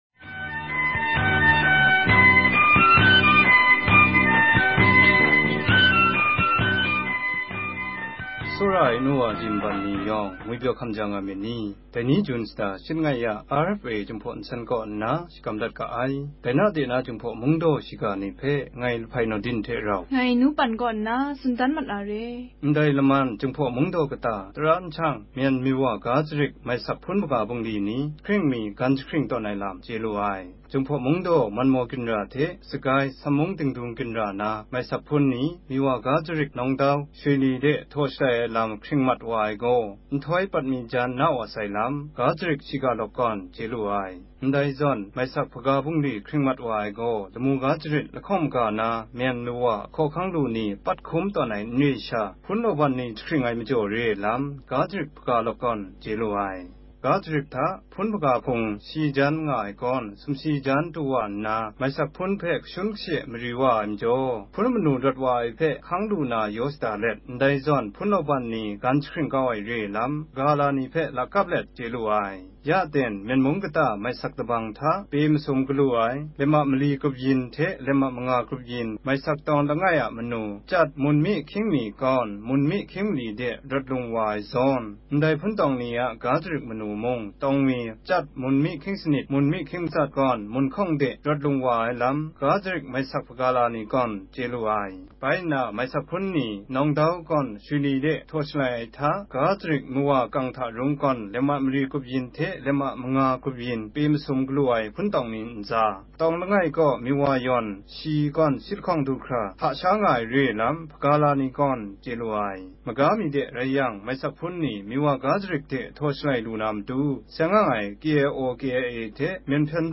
ကချင်ဘာသာ အသံလွှင့် အစီအစဉ်များ
လွတ်လပ်တဲ့အာရှ အသံ (RFA) မှ တိုင်းရင်းသား ဘာသာ အစီအစဉ်များကို ထုတ်လွှင့်နေရာမှာ အပတ်စဉ် တနင်္လာနေ့ မနက်ပိုင်းမှာ ကချင်ဘာသာဖြင့် သတင်းနှင့်ဆောင်းပါးများ အသံလွှင့်ပေးလျက် ရှိပါတယ်။